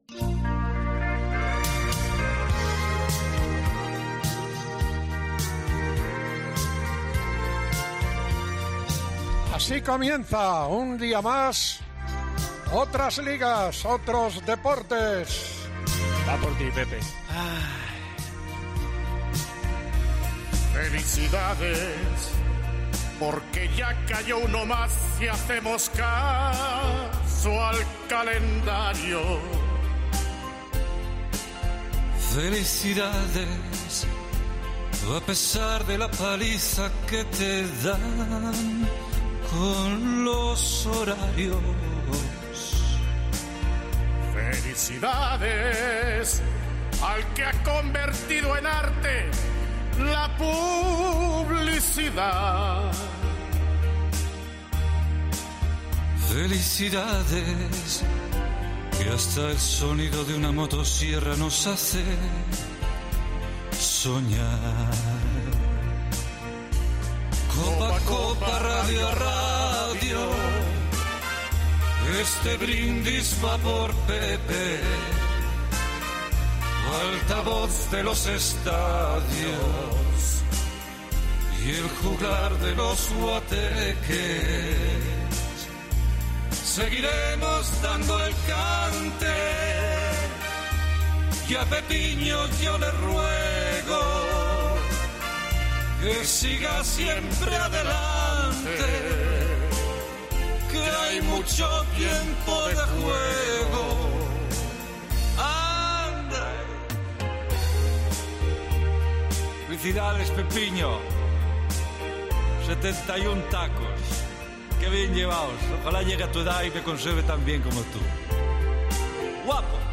Una canción